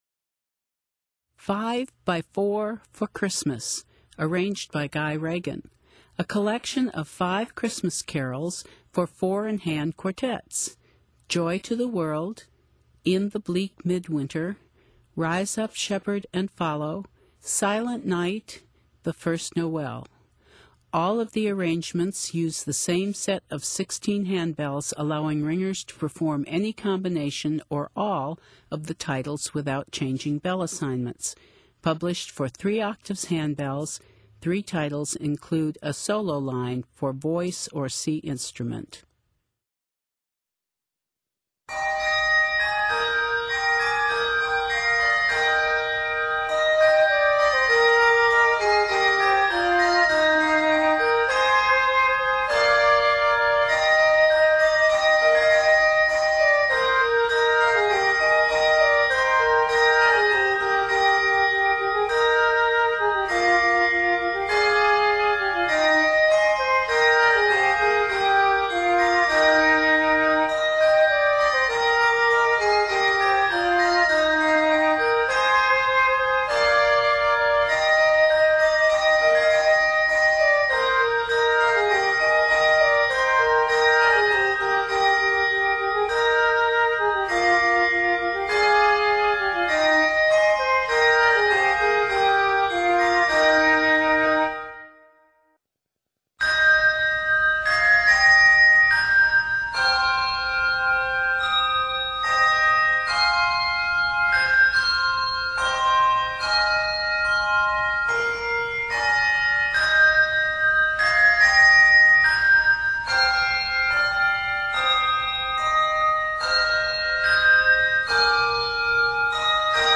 four-in-hand quartet
4-in-hand ensemble , Quartet